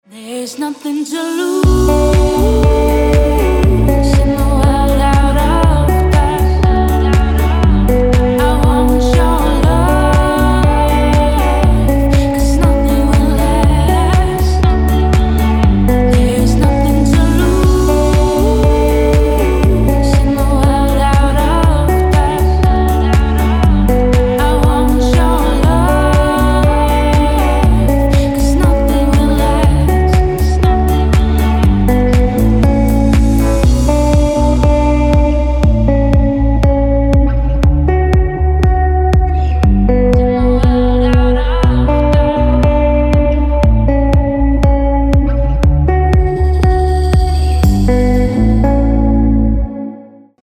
красивые
deep house
спокойные
красивый женский голос